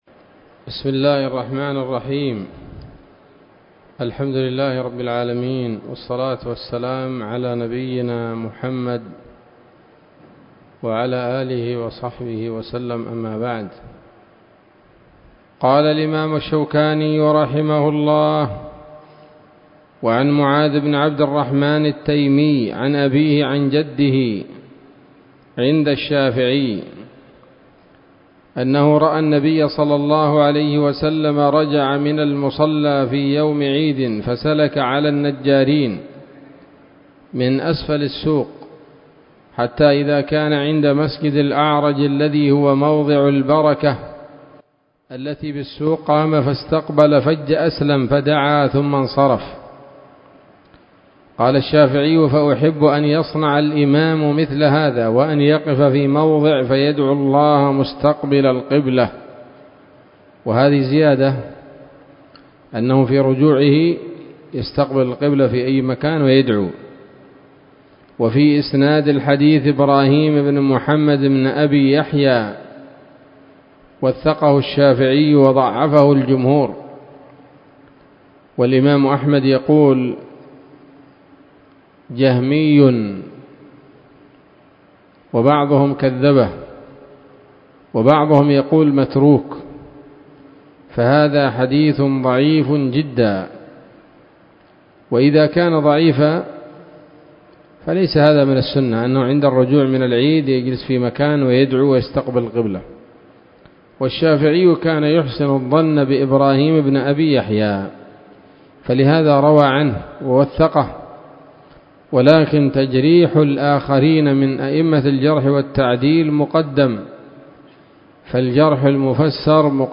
الدرس الخامس من ‌‌‌‌كتاب العيدين من نيل الأوطار